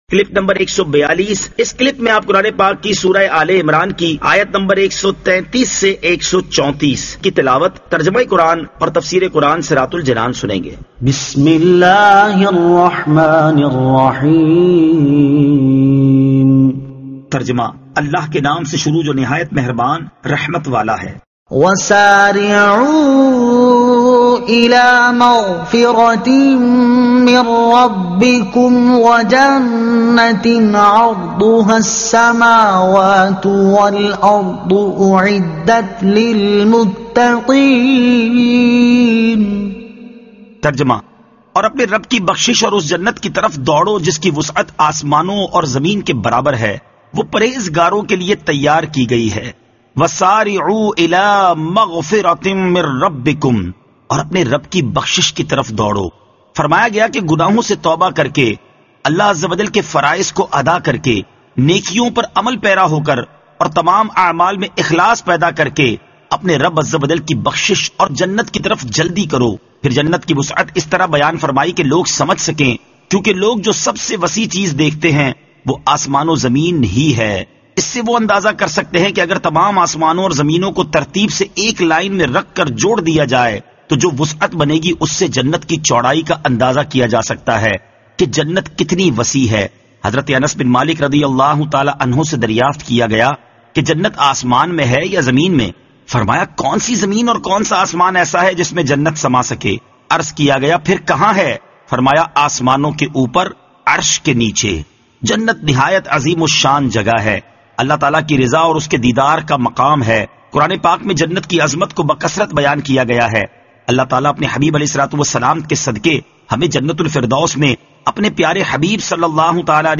Surah Aal-e-Imran Ayat 133 To 134 Tilawat , Tarjuma , Tafseer